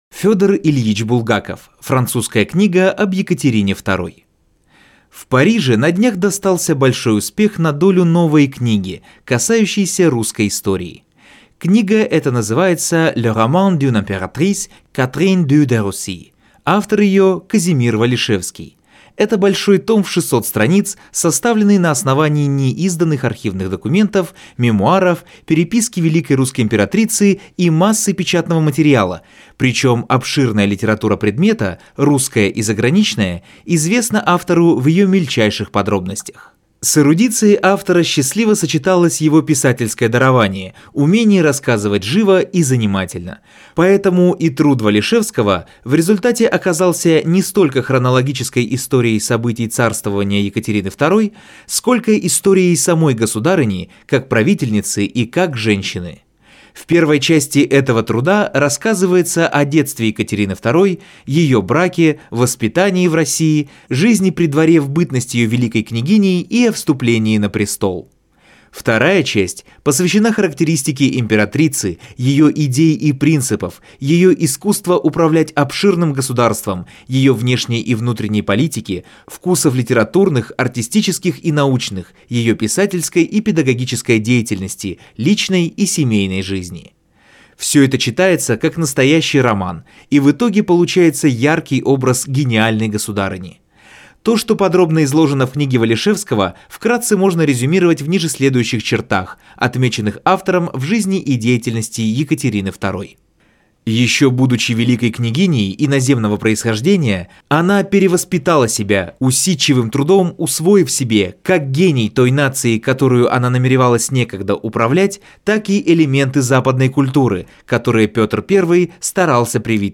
Аудиокнига Французская книга об Екатерине II | Библиотека аудиокниг